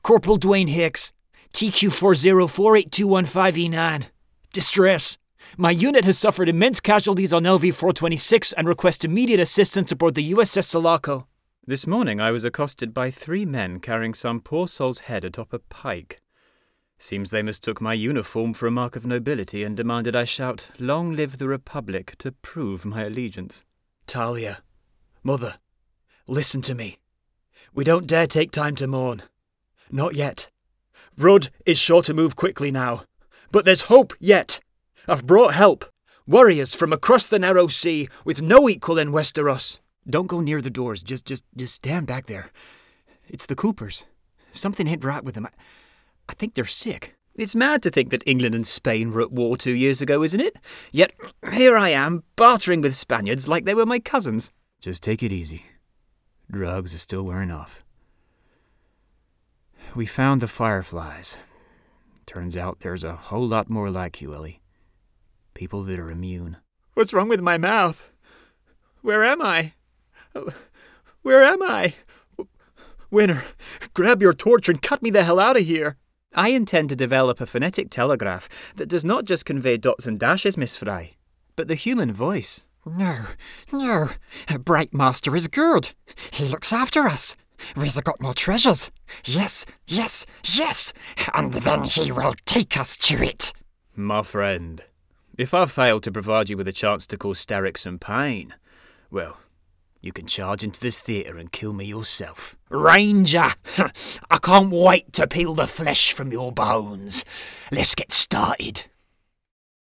Very versatile with a huge range of accents.
• Male